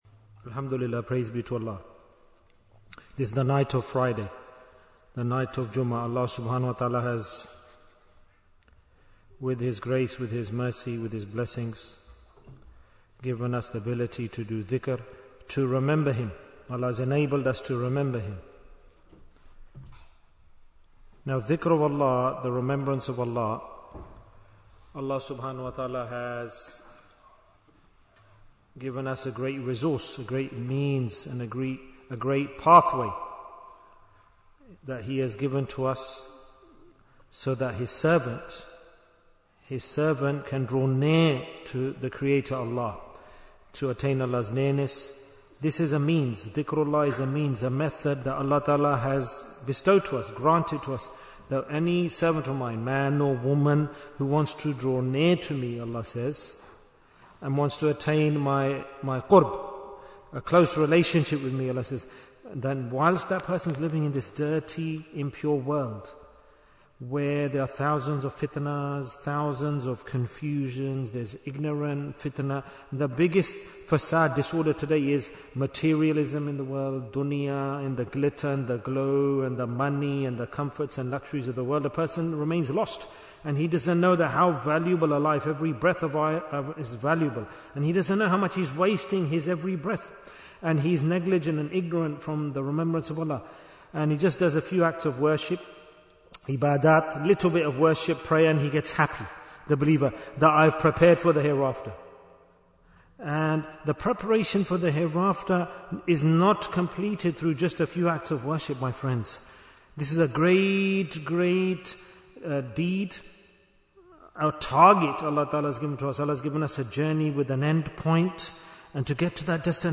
Advice of a Naqshbandi Sheikh to his Mureed Bayan, 30 minutes15th May, 2025